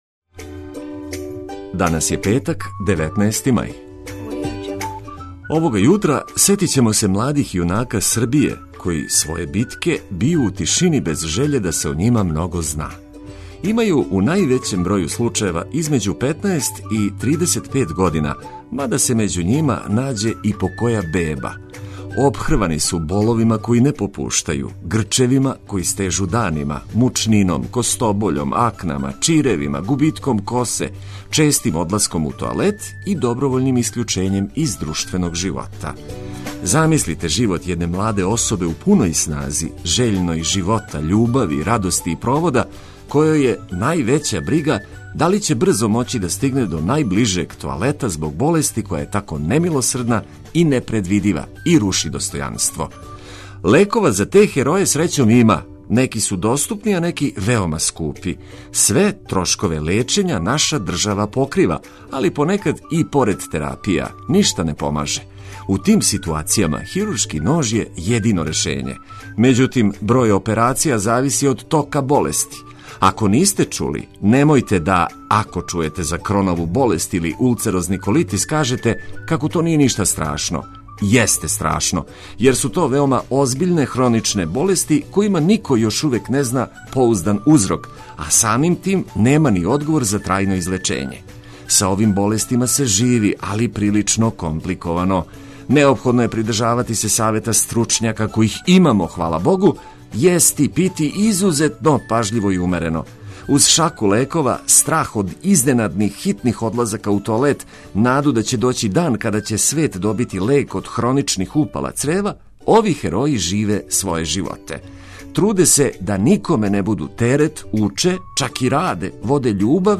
Ми смо ту, спремни да вам буђење учинимо пријатнијим уз одличну музику и приче о догађајима који су пред нама.